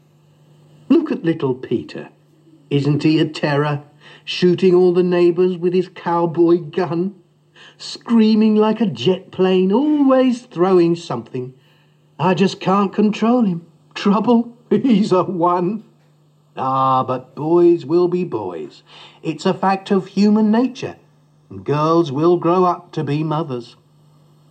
Boyswillbeboys_stanza1.mp3